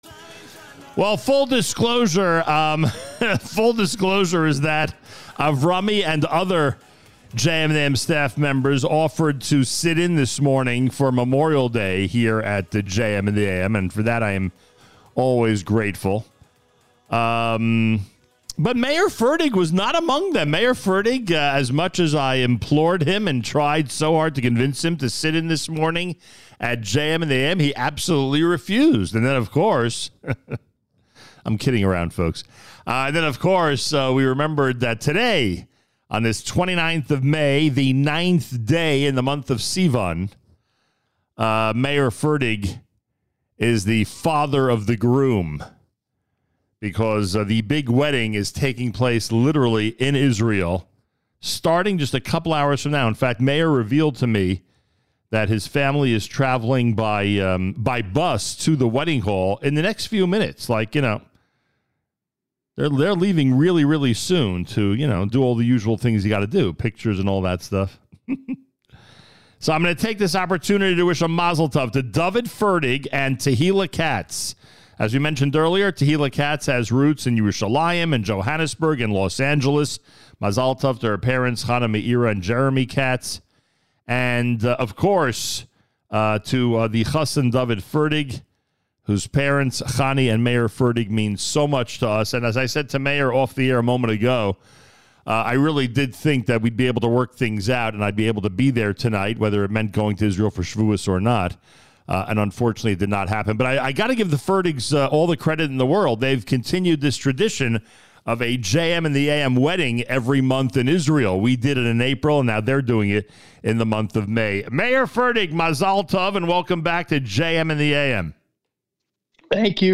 | Category : Interviews, News